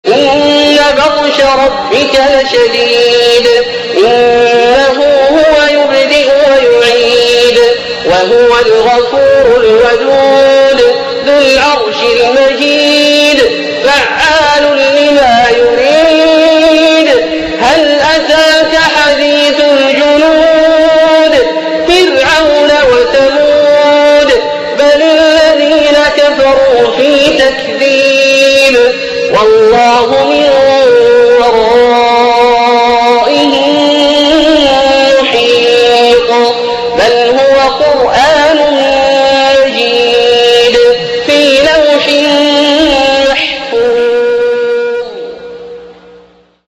من هو هذا القارئ :